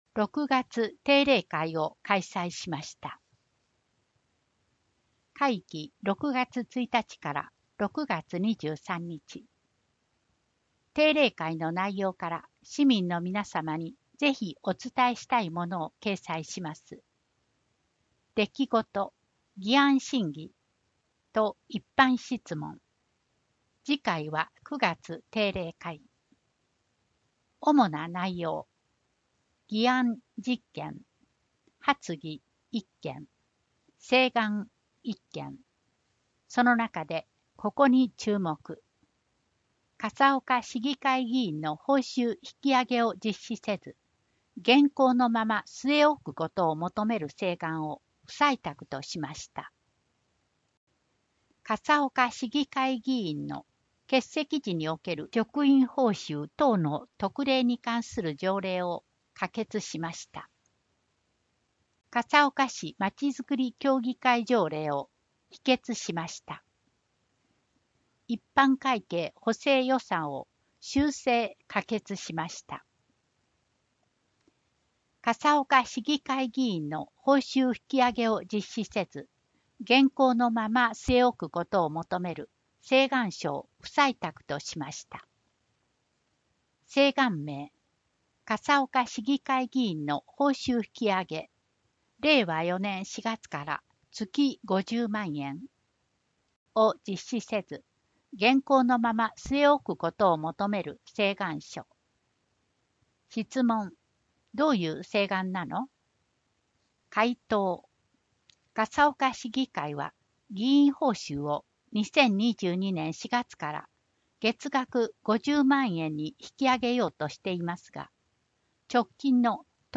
市議会だより173号（音訳版） - 笠岡市ホームページ
一問一答 [その他のファイル／9.01MB] 26分14秒 8 裏表紙 市政相談会を開催しています [その他のファイル／606KB] 1分43秒 9 裏表紙 9月定例会のお知らせ [その他のファイル／782KB] 2分13秒 10 裏表紙 編集の窓 [その他のファイル／560KB] 1分35秒 （音声データ提供 笠岡音訳の会） 1時間5分40秒